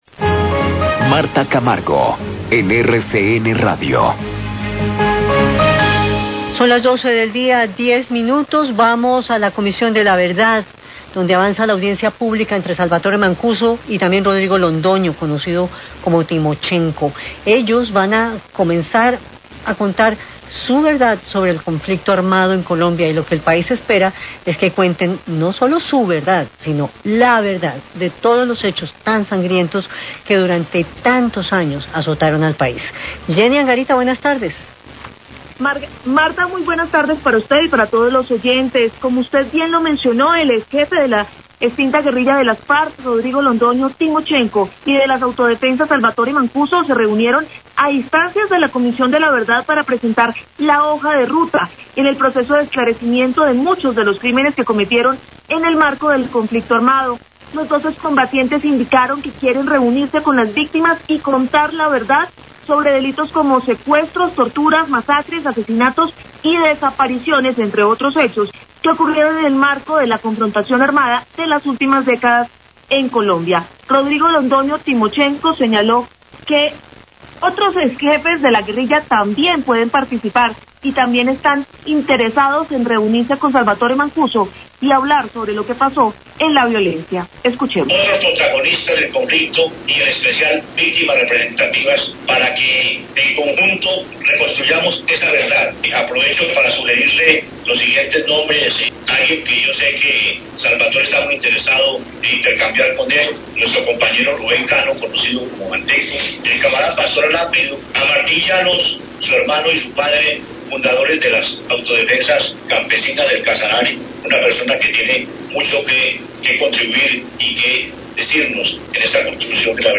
Declaraciones de Salvatore Mancuso y Rodrigo Londoño ante audiencia virtual de la Comisión de la Verdad
Declaraciones de Salvatore Mancuso, desde una cárcel en los Estados Unidos, y Rodrigo Londoño durante la audiencia virtual ante la Comisión de la Verdad donde presentaron la hoja de ruta para contar la verdad sobre el conflicto armado que los enfrentó.